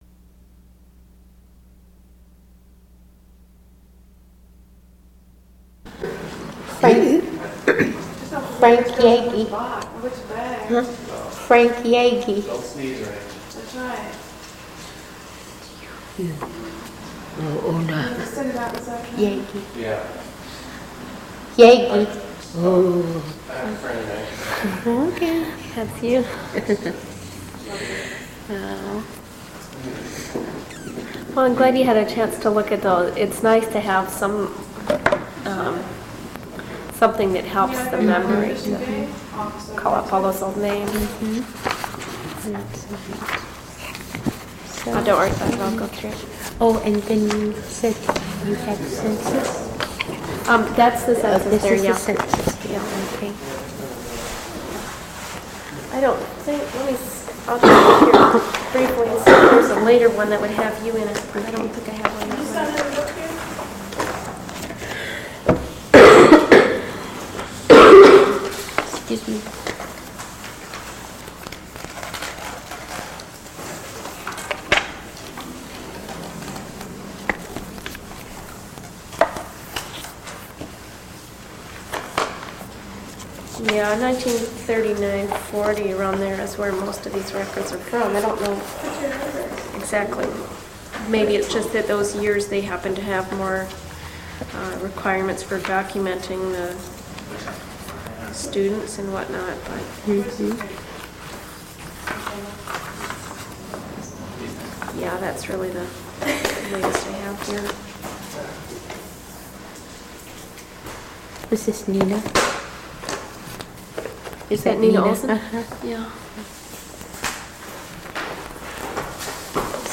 Quality: poor in places. (In English) Location: Location Description: Kodiak, Alaska